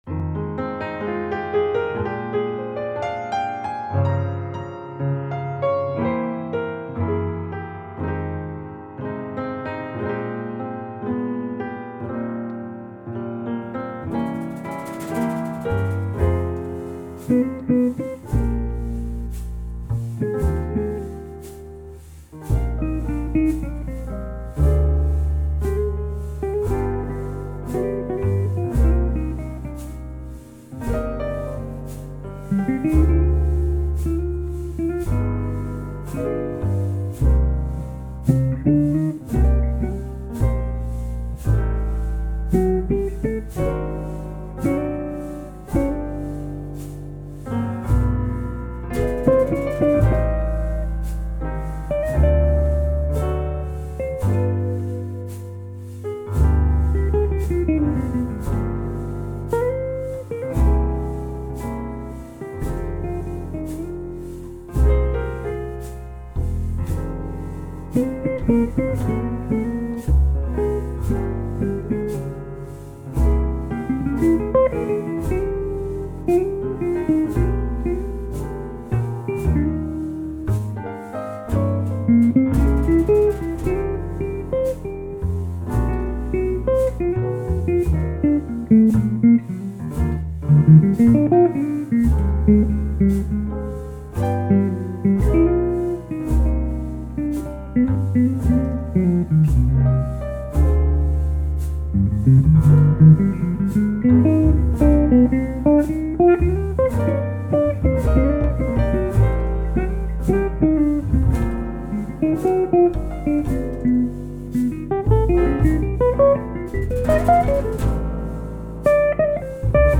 Jazz News
Jazz Band